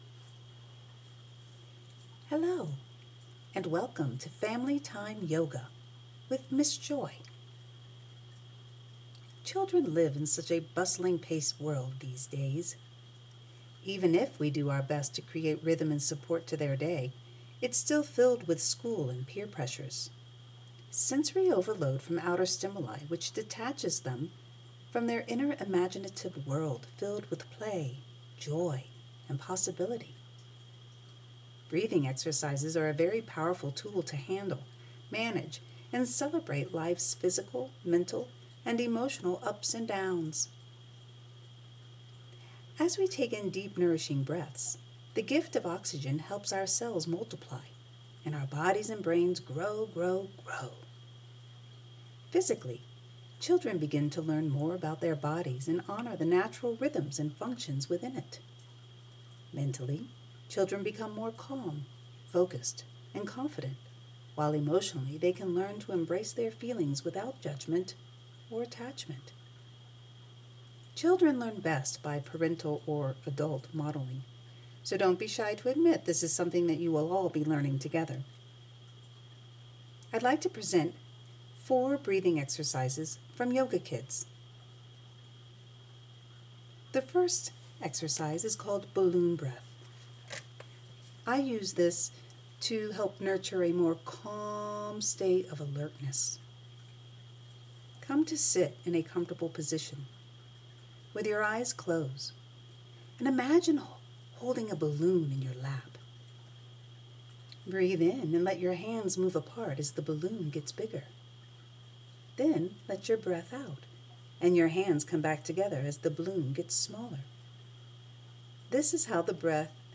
Kid’s Yoga Breathing Practice with four different breathing exercises (7 Mins.)
kidsbreathingexercises.m4a